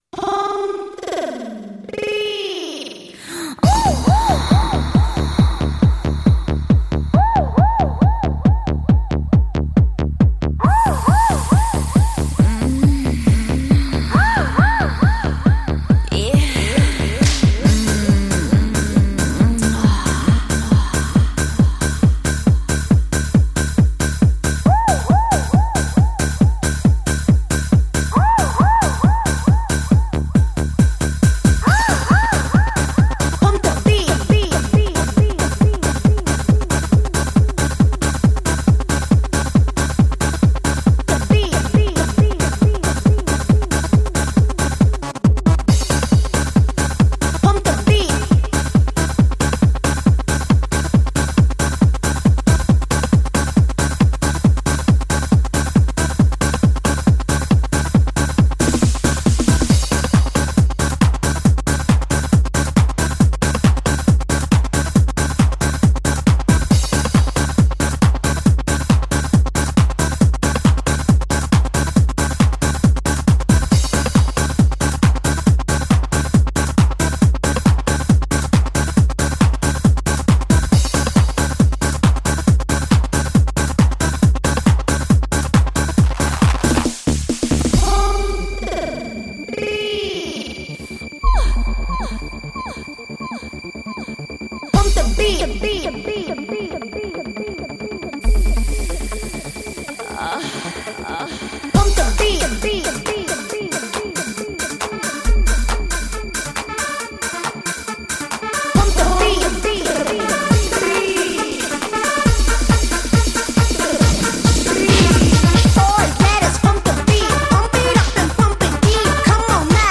Жанр: Club